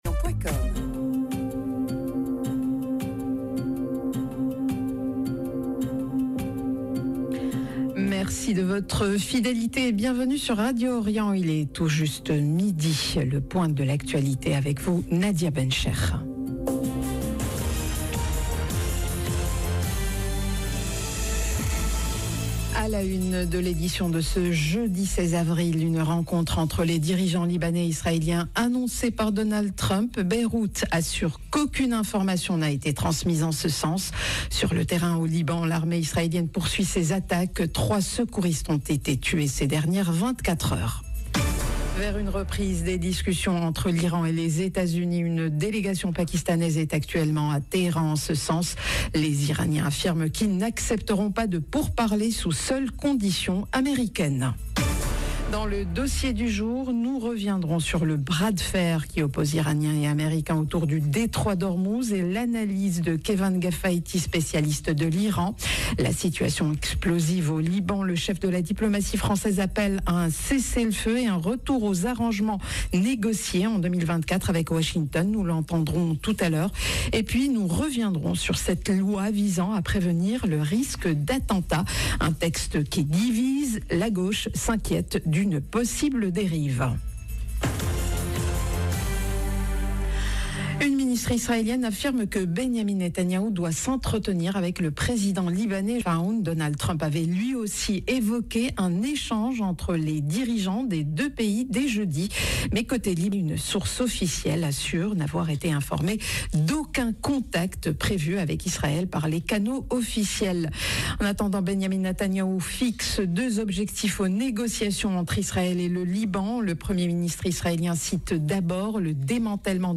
Journal de midi